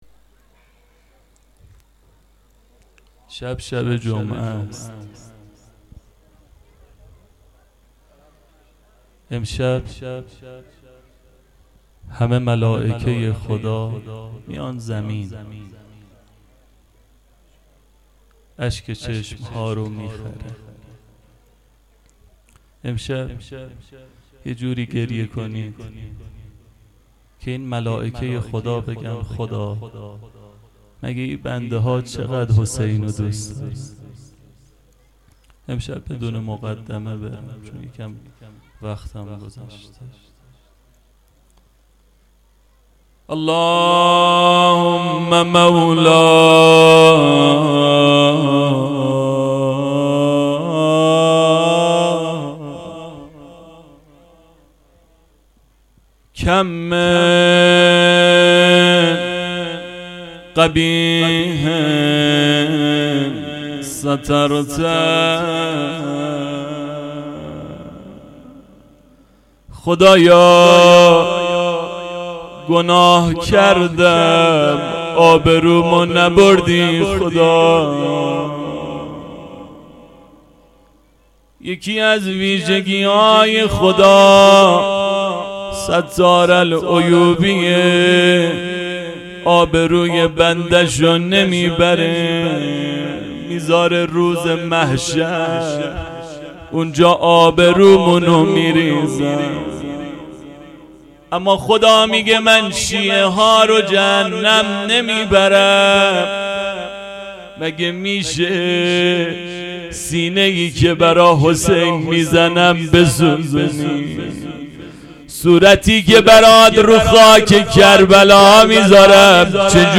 روضه شب چهارم محرم ۹۷